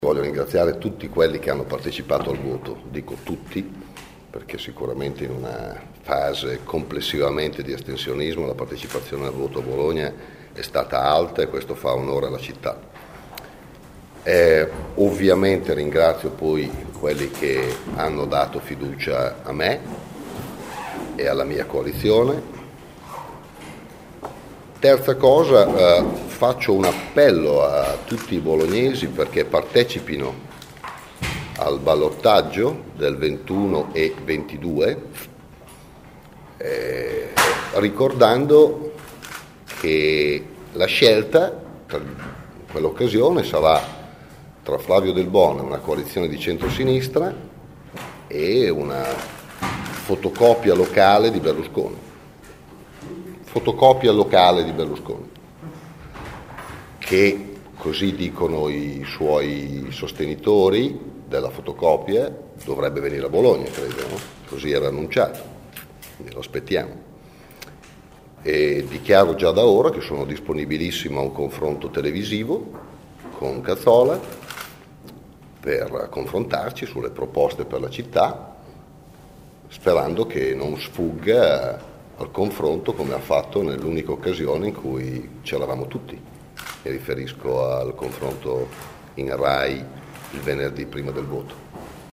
Ha detto così questa mattina in una breve conferenza stampa convocata nella sede del Pd.